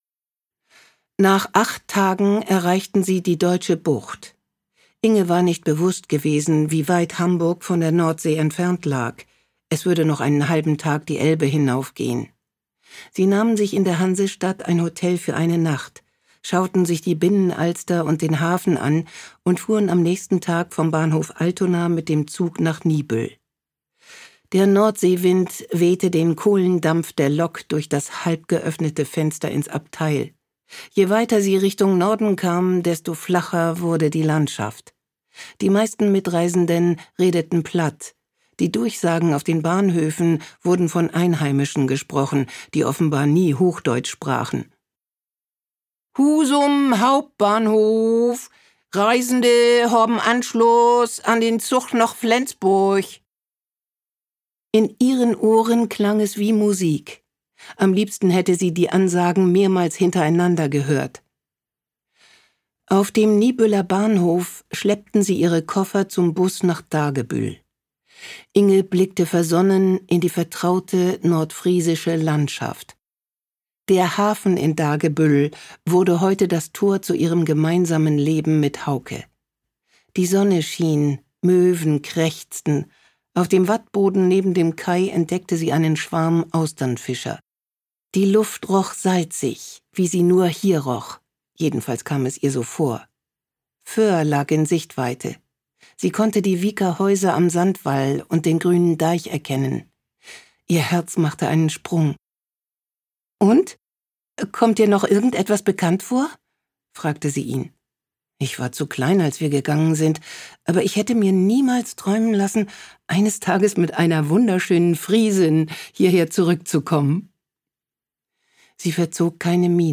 Ungekürzte Lesung mit Sabine Kaack
Sabine Kaacks dunkle, prägnante Stimme hat jeder im Ohr, der jemals Diese Drombuschs gesehen hat. Charmant, lebendig und frisch macht sie jedes Hörbuch zu einem Erlebnis.